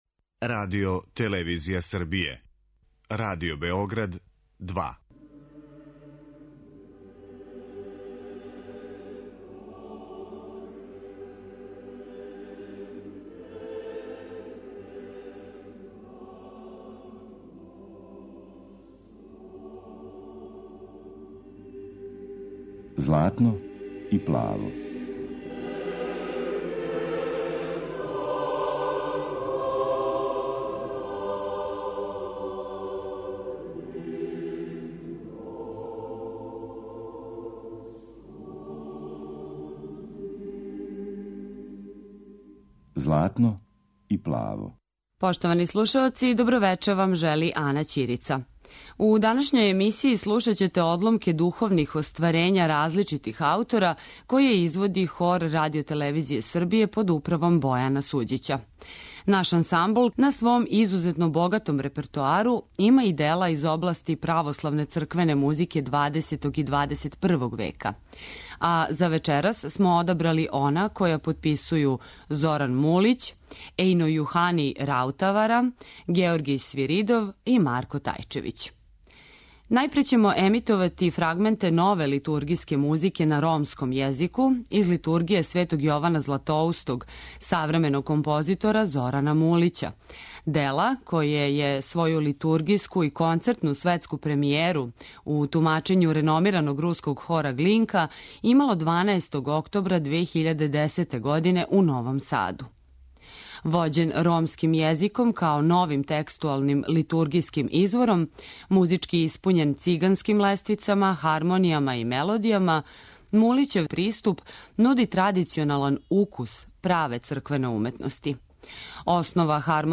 Црквена дела XX и XXI века